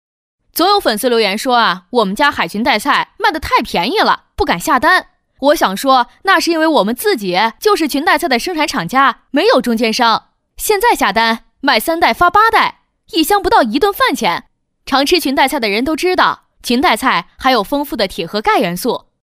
女285-抖音带货-【裙带菜-口语朴素】
女285-明亮柔和 素人自然